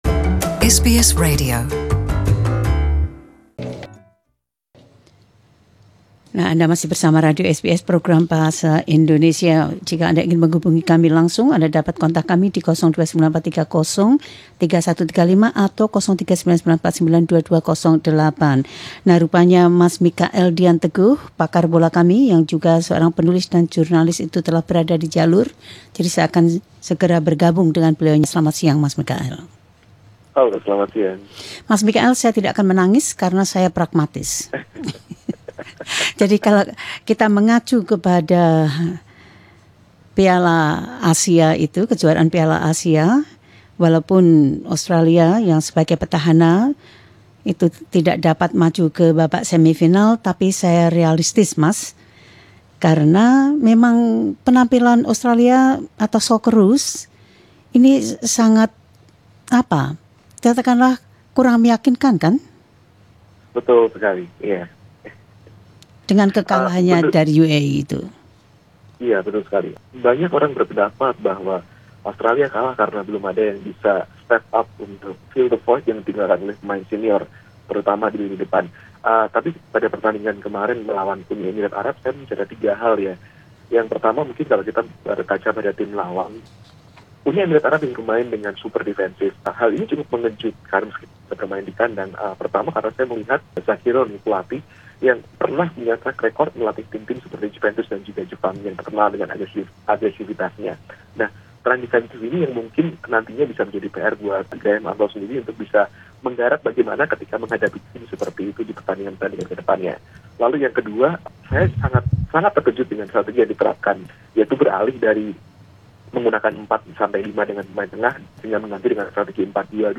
Jurnalis, novelis dan komentator